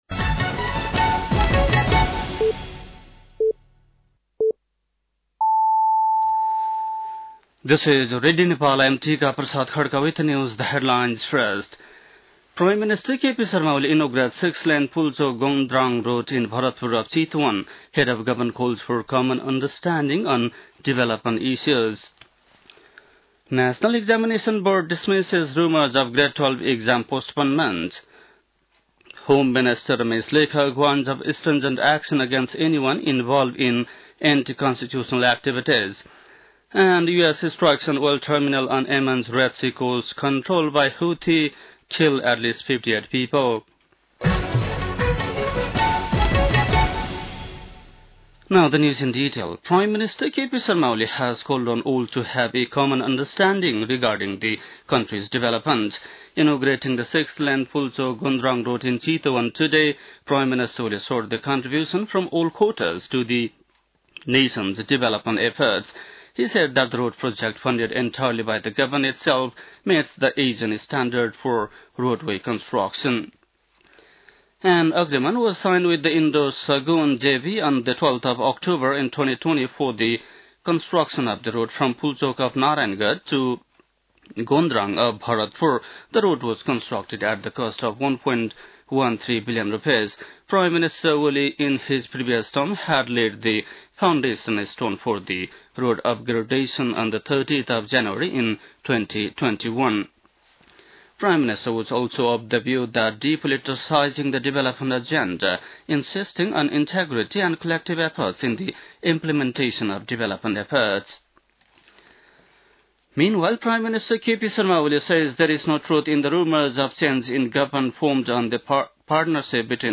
बेलुकी ८ बजेको अङ्ग्रेजी समाचार : ५ वैशाख , २०८२
8-pm-english-news-1-05.mp3